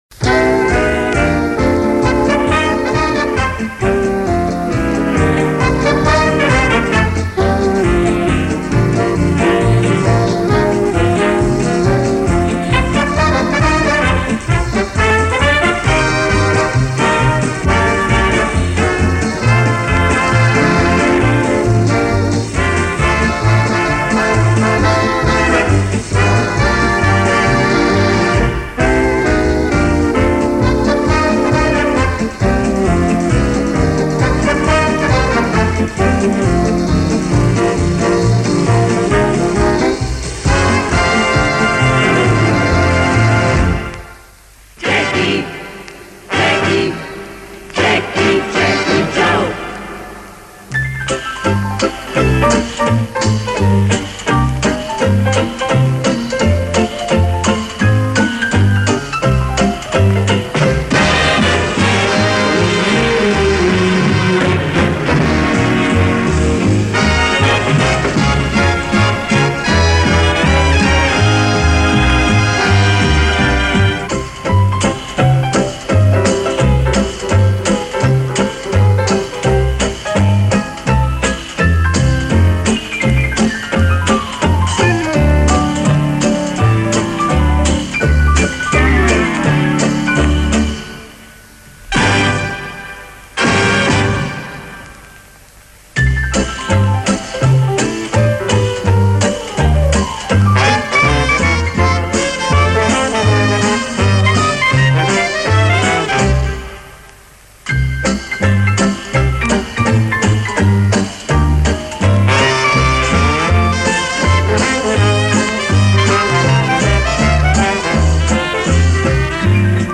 Предлагаю фрагмент фонограммы: